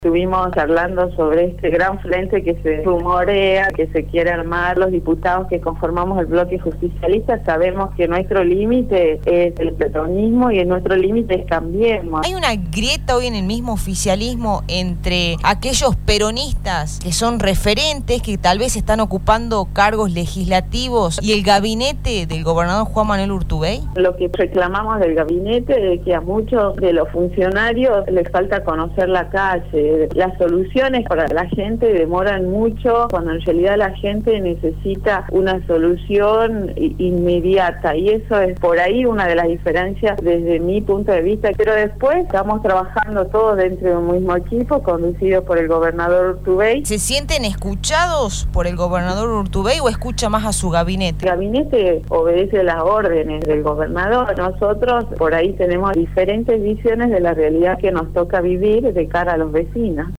La Diputada Provincial Liliana Guitián en diálogo con Radio Dinamo habló sobre la grieta en el PJ y al ser consultada por el gabinete provincial dijo que las soluciones para la gente demoran mucho.